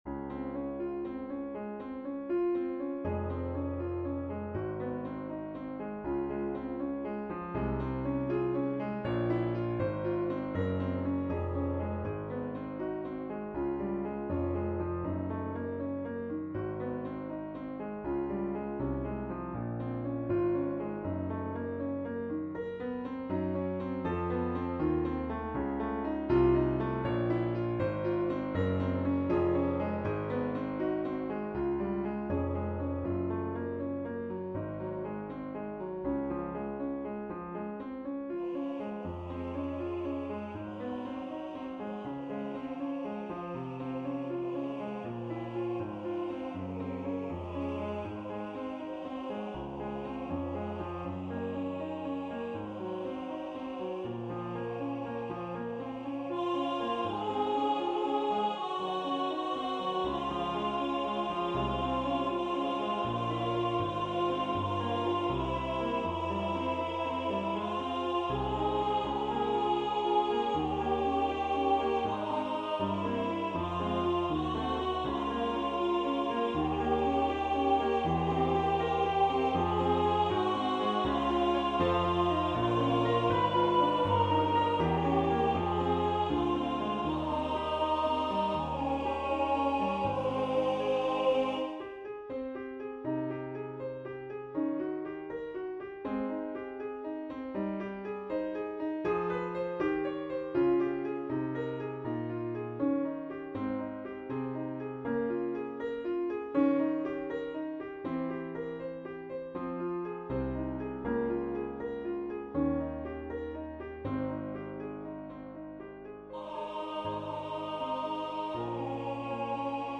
Cantique_de_Jean_Racine_alto.mp3